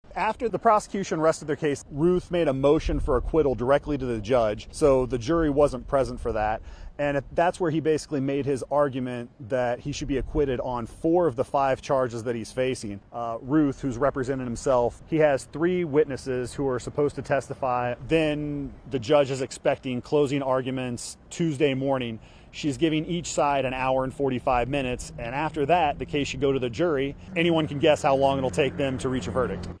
reports from Fort Pierce, Florida